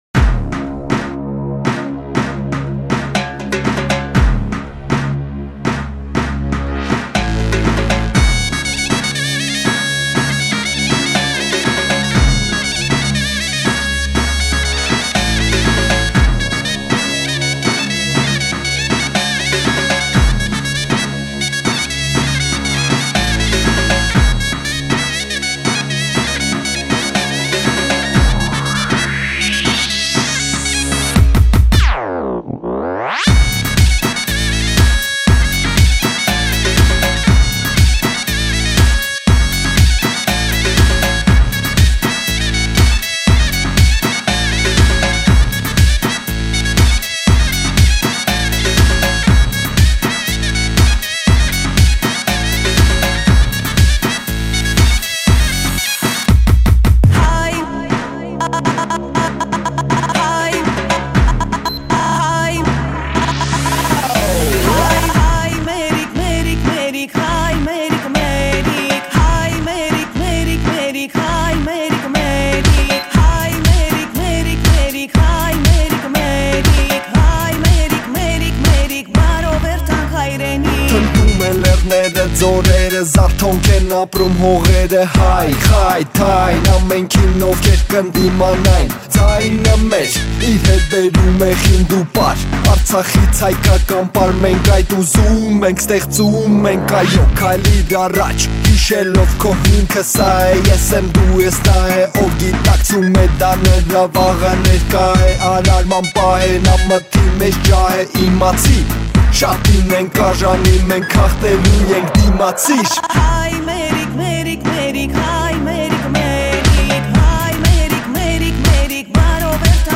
թույն ռաբիզ երգ, շարան